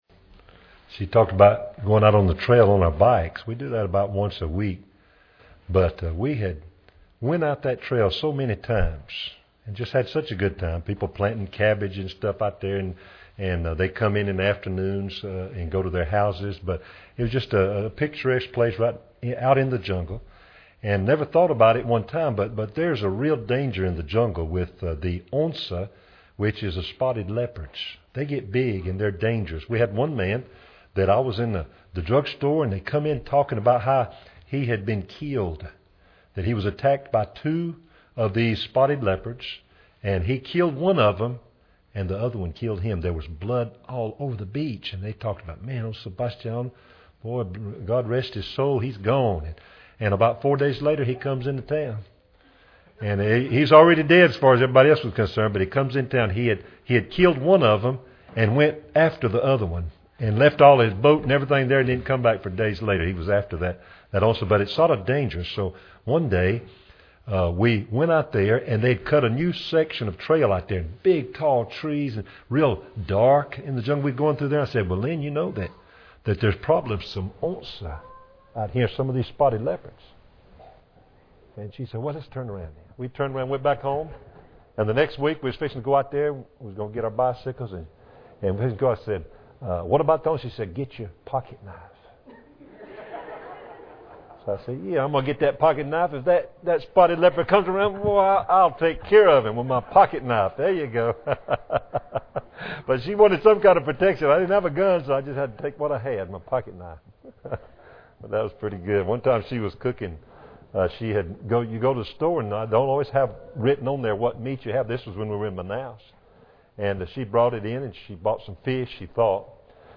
Service Type: Special Service